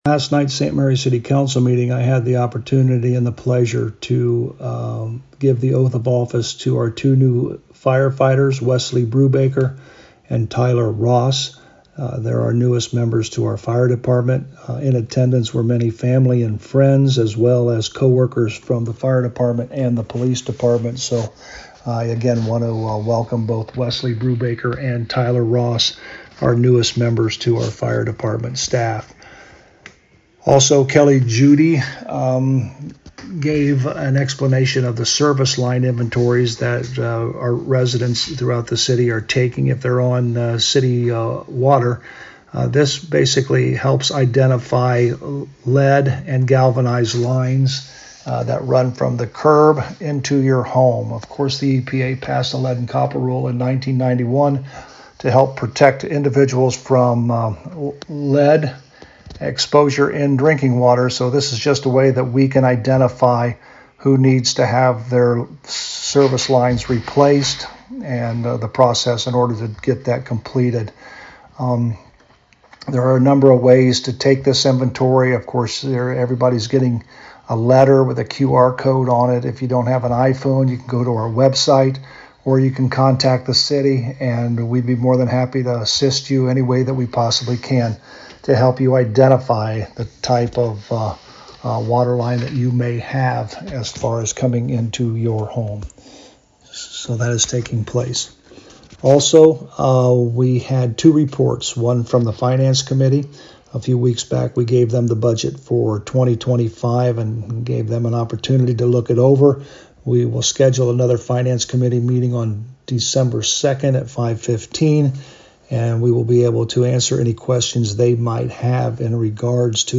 Local News
To hear a summary with St Marys Mayor Joe Hurlburt: